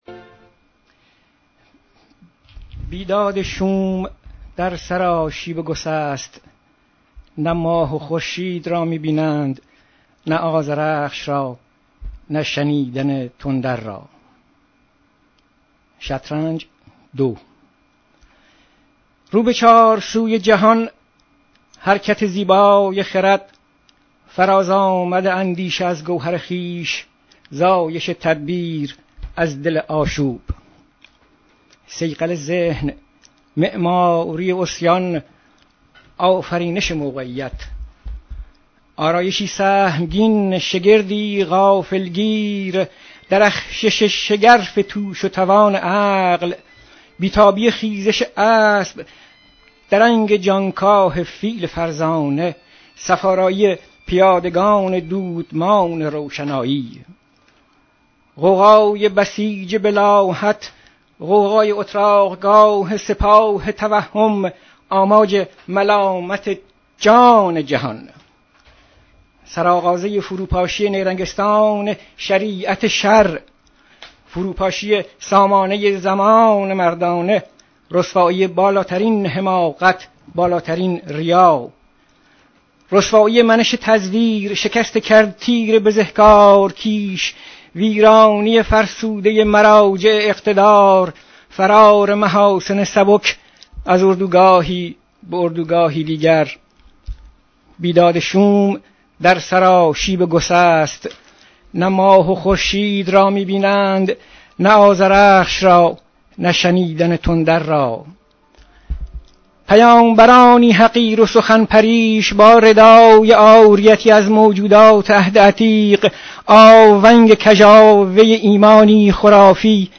شعری از روشنک